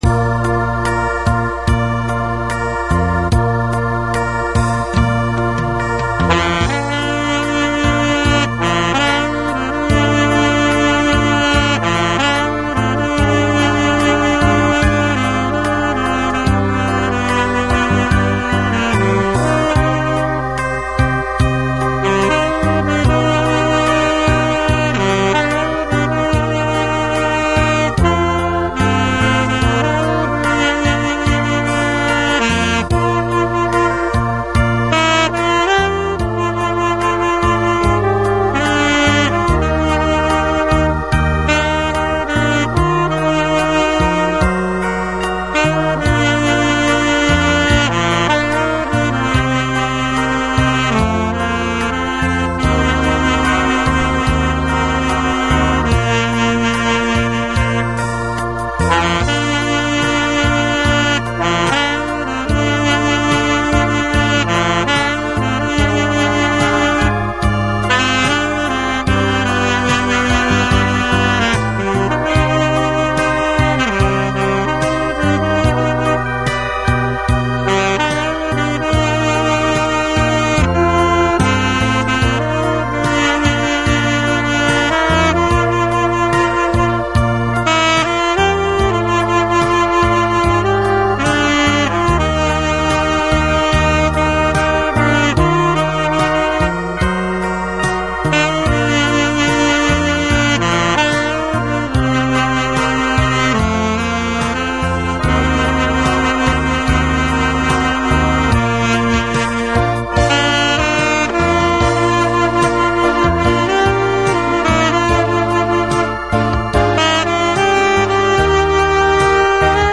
색소폰
긴 호흡이 필요하므로
앨토